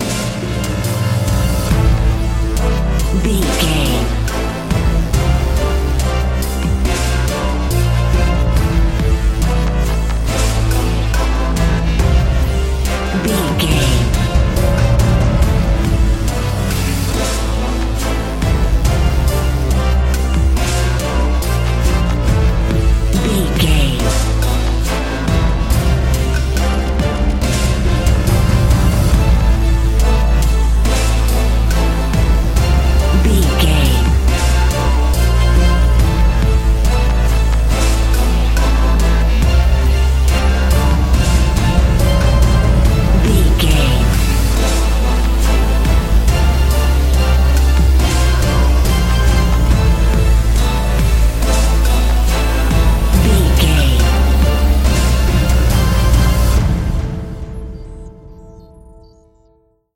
Epic / Action
Aeolian/Minor
brass
drum machine
percussion
orchestra
driving drum beat